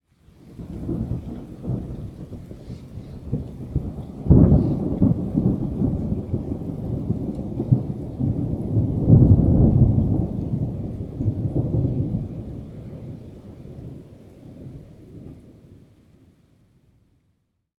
thunderfar_12.ogg